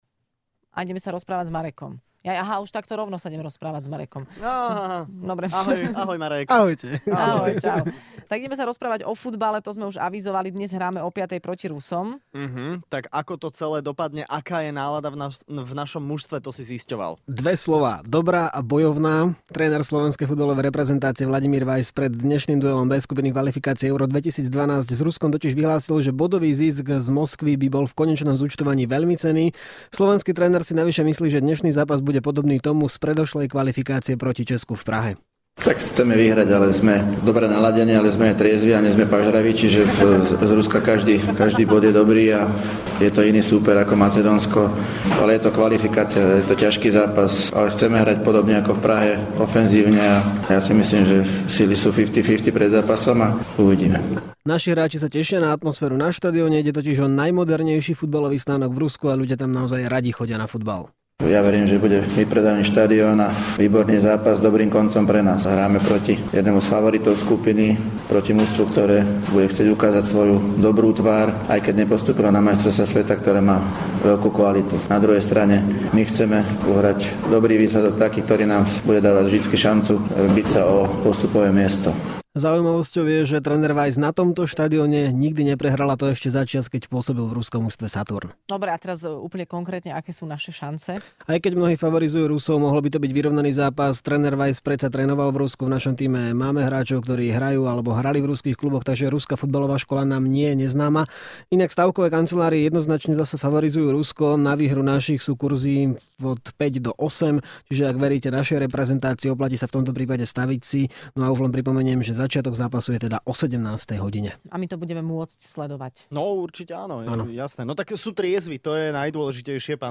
Počasie si s nami stále robí čo chce, a tak sme si do štúdia zavolali klimatológa